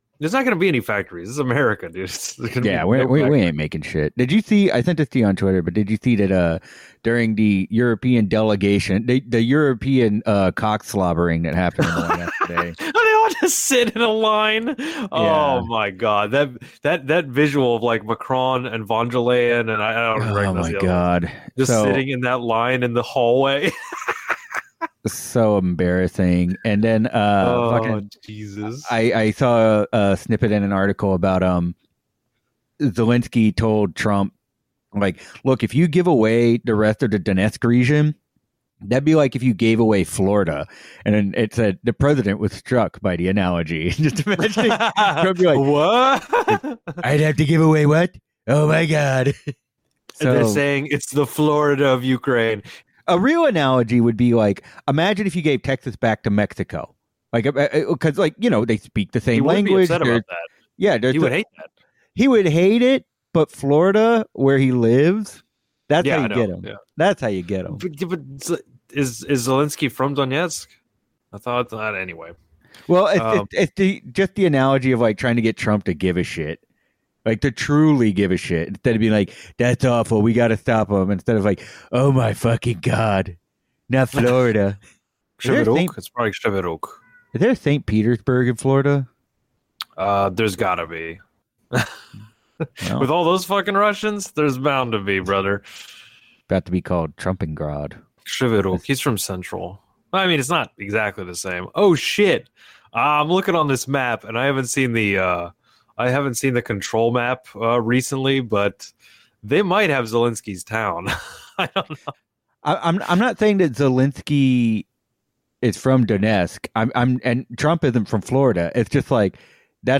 Two lifelong friends and propaganda lovers from the Gulf Coast look at and discuss media portrayals of politics from ongoing news media narratives to film and television.